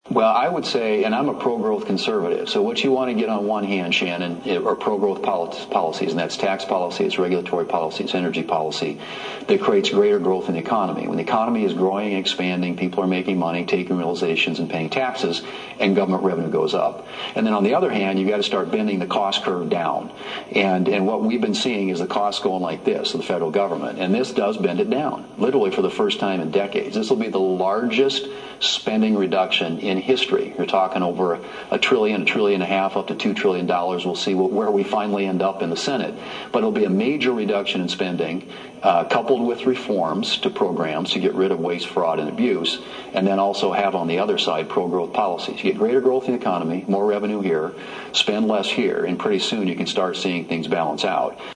WASHINGTON, D.C.(HubCityRadio)- Fox News Sunday’s host Shannon Breem did a pre-recorded interview with U.S. Senate Majority Leader John Thune which aired on Sunday.